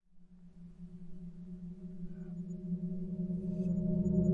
电风扇金属烤架采样 " 电风扇烤架空气中的金属撞击反向
描述：电风扇作为打击乐器。击打和刮擦电风扇的金属格栅可以发出美妙的声音。
Tag: 金属的 混响 电动风扇 样品